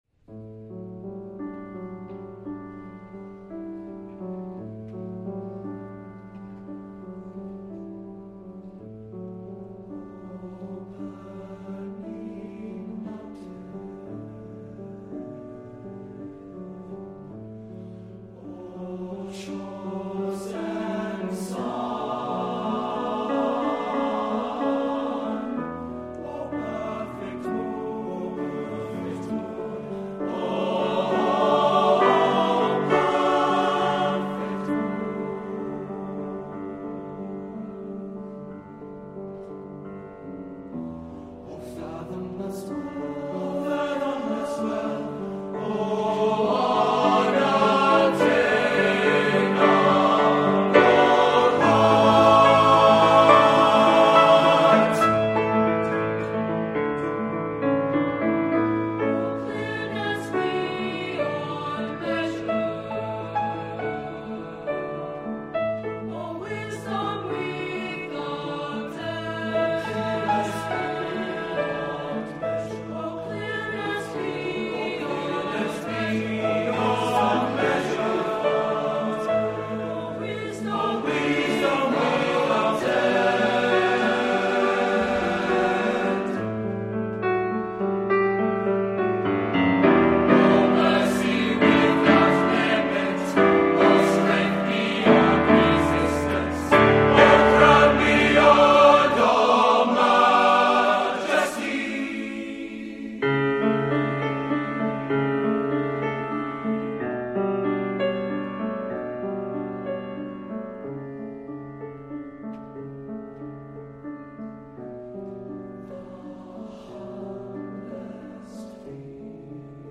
Voicing: TTB/TBB